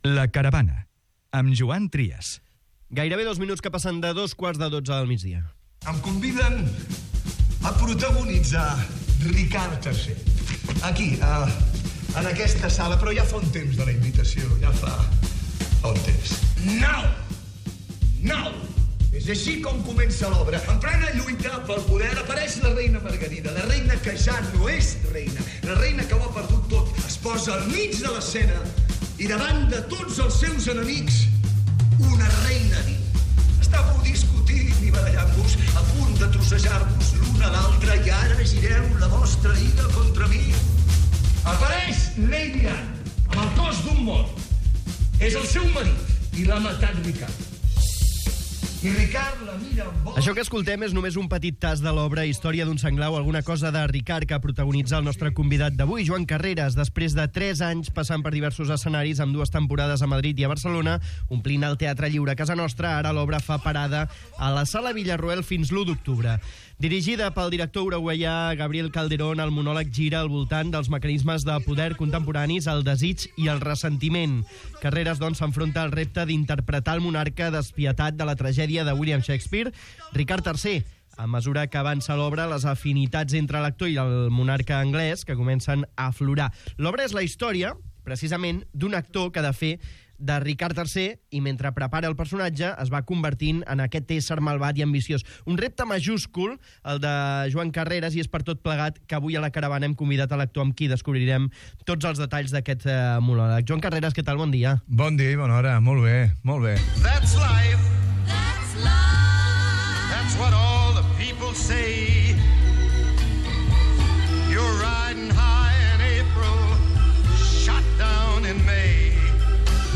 Recupera la conversa